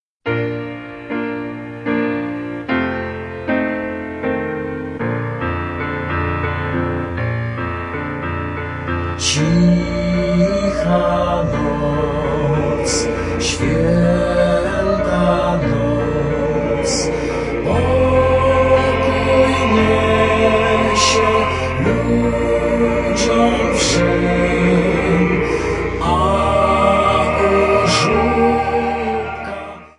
Polish Christmas Carols